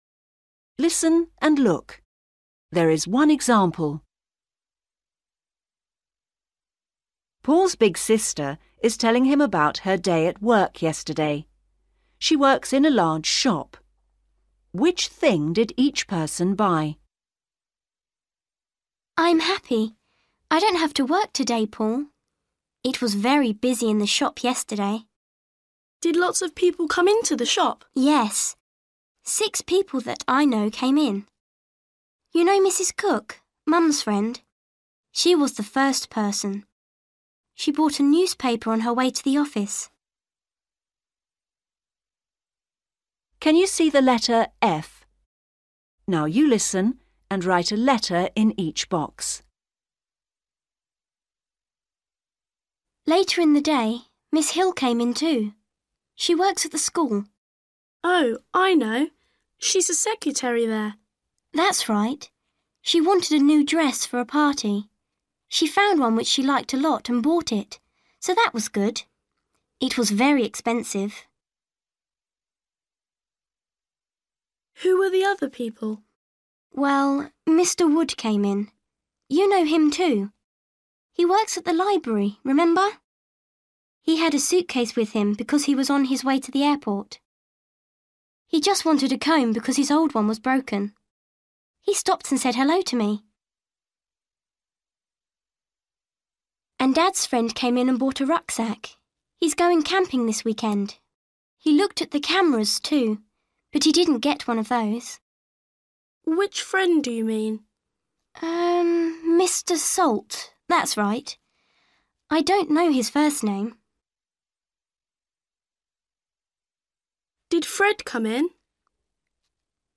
Paul’s big sister is telling him about her day at work yesterday.